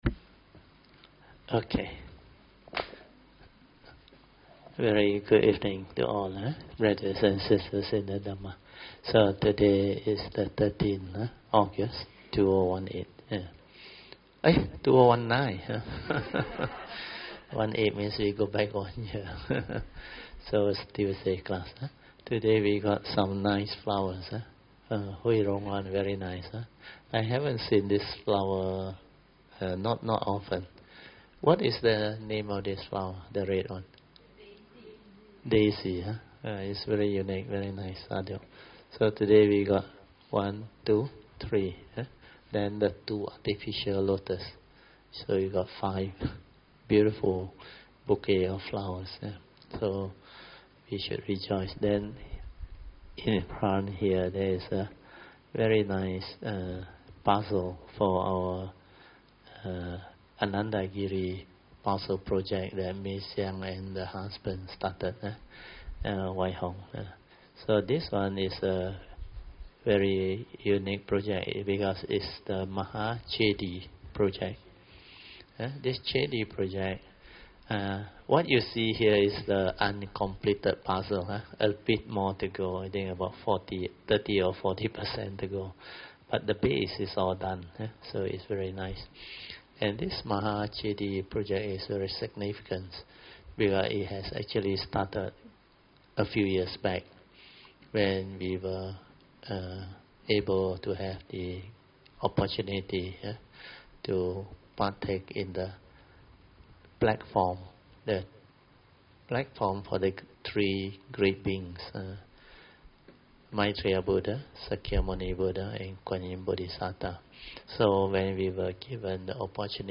Tuesday Class